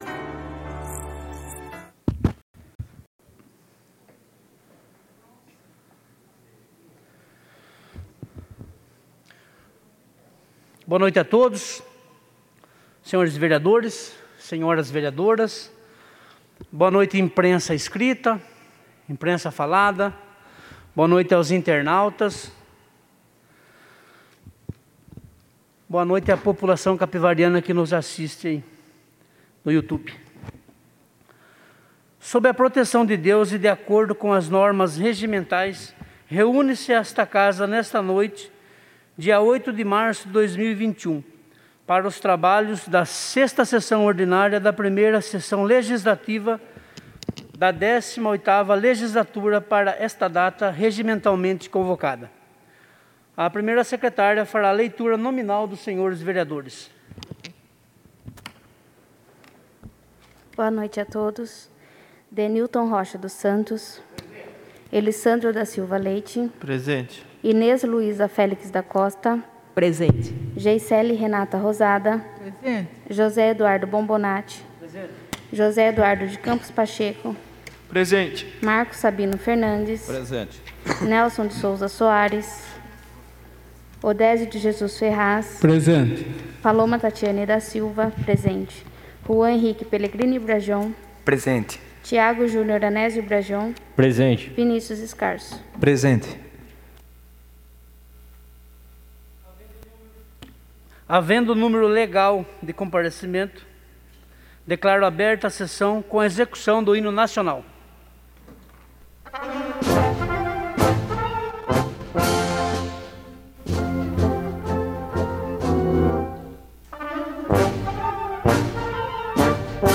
CAPIVARI Câmara Municipal de Capivari ÁUDIO – Sessão Ordinária 08/03/2021